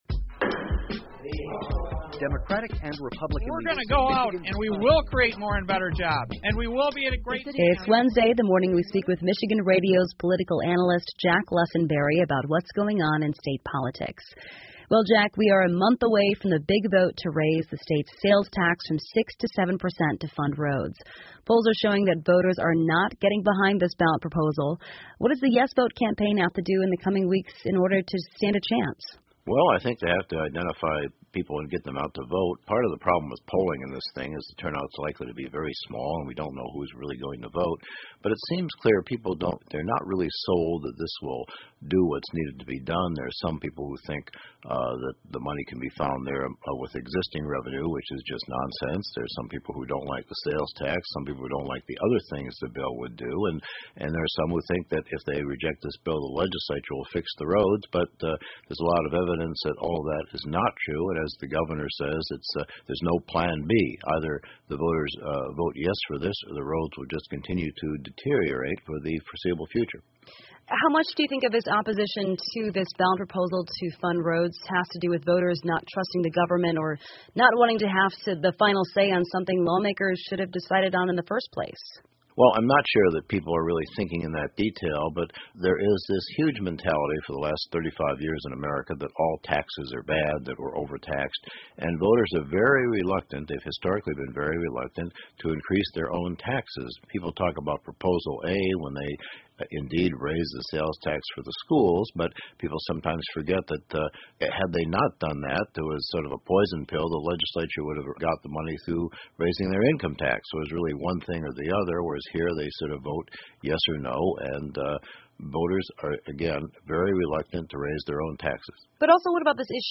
密歇根新闻广播 为什么人们不支持公路建设资金的提案 听力文件下载—在线英语听力室